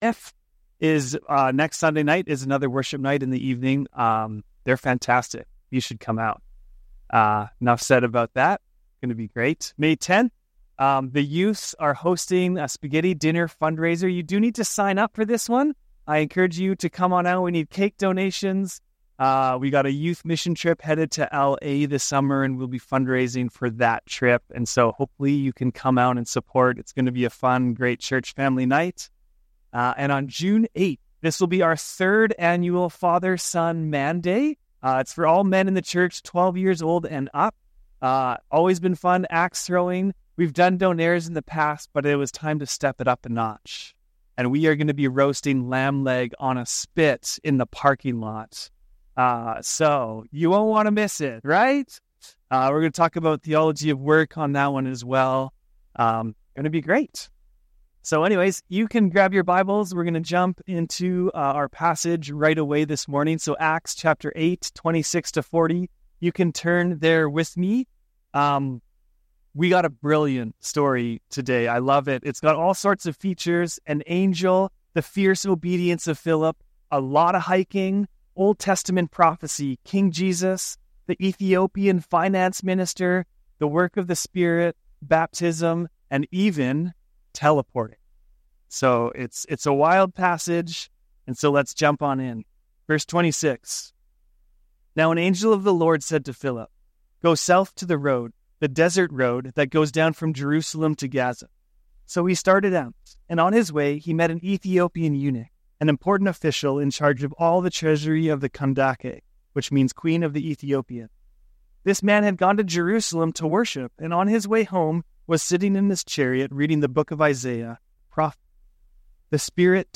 Sermons | North Shore Alliance Church
NSAC 75th Anniversary Service